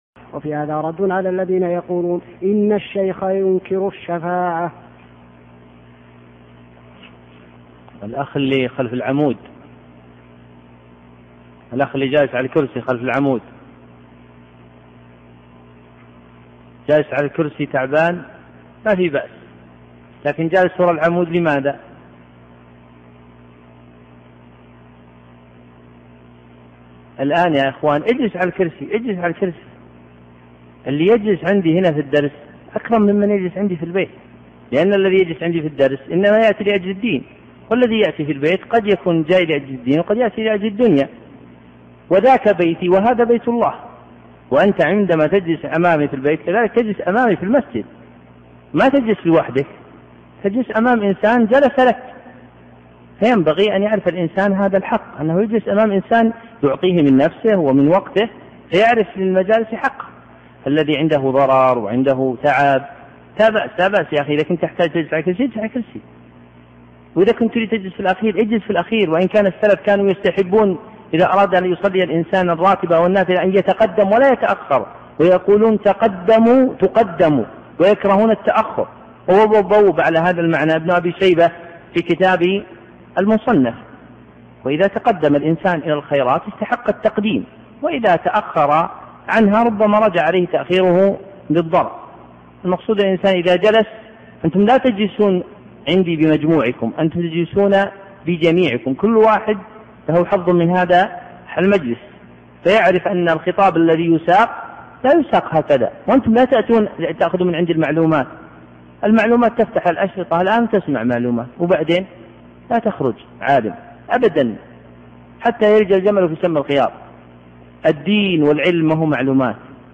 112. موعظة في أدب الدرس